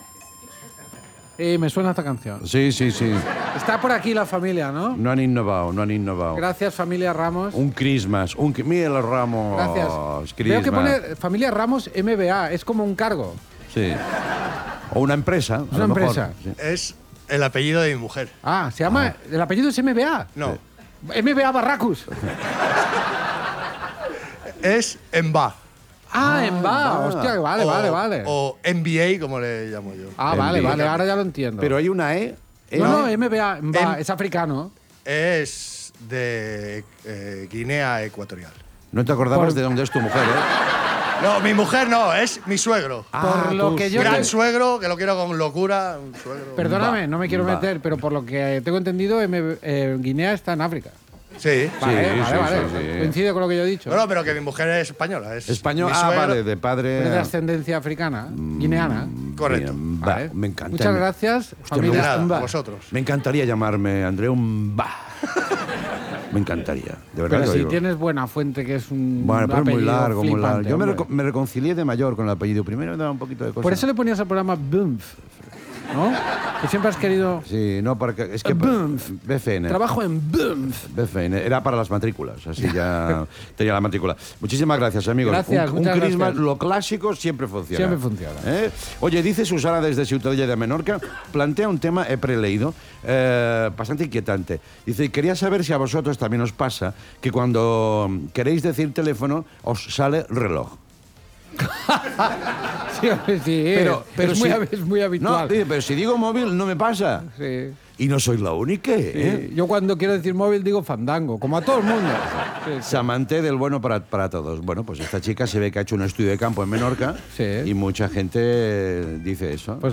Fragment final de l'episodi 400. Respostes a escrits de l'audiència, agraïment a l'acollida de la sèrie "El otro lado" fotos d'Andreu Buenafuente dormint, campanades de cap d'any fetes per la imitació del cuiner Ferran Adrià i el Profesor Polip
Entreteniment